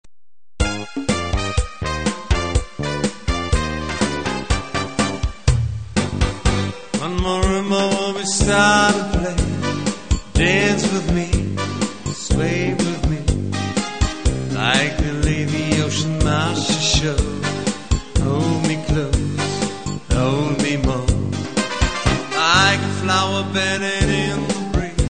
Tanz und Unterhaltungsmusik
• Coverband